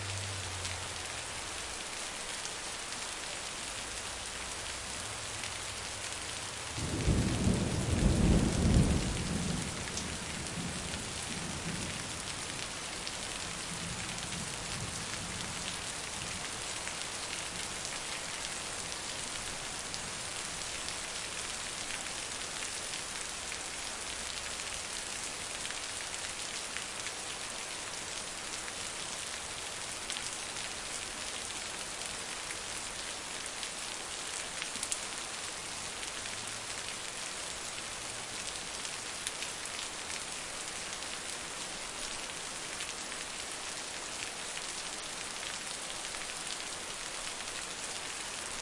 瓢泼大雨
描述：在城市里录制的瓢泼大雨，还伴有打雷声。
标签： 下雨 大雨 拟音 雷雨 打雷 音效
声道立体声